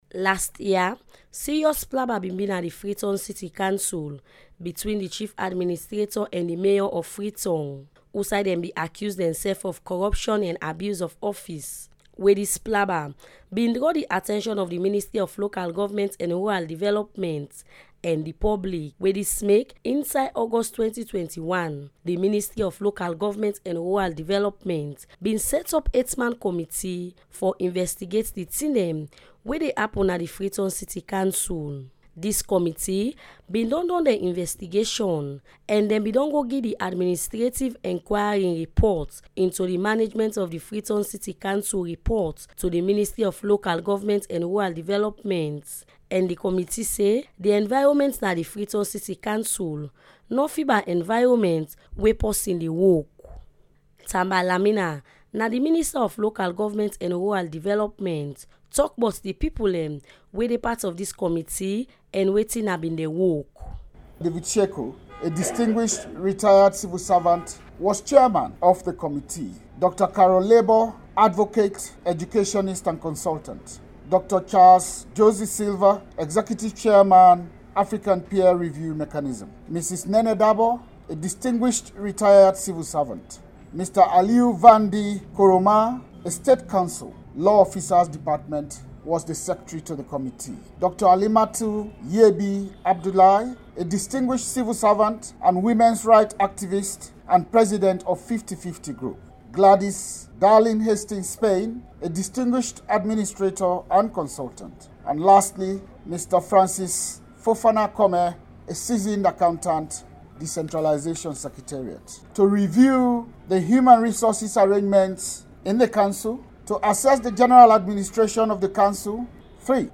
This is minister Lamina speaking on Radio Democracy last Thursday about the report:
Tamba-Lamina-speaking-on-Radio-Democracy-on-the-publication-of-report-on-management-of-Freetown-City-Council.mp3